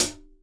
Closed Hats
Boom-Bap Hat CL 50.wav